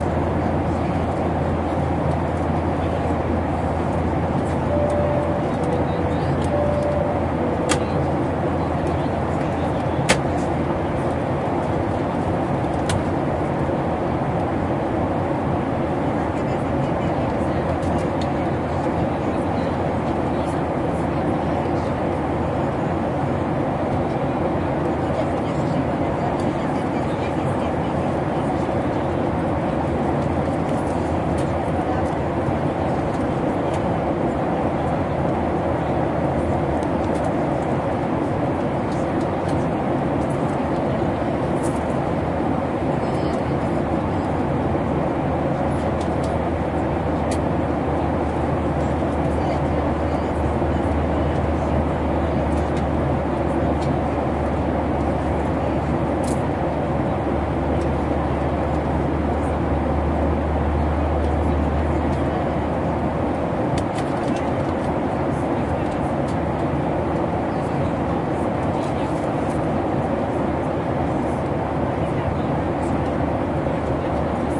机上氛围环绕 " 机上氛围MS
描述：zoom h2n ms
Tag: 机器 环境 jetfield记录 飞机 airplaneaircraft 飞机 舱内噪音 ATMO 立体声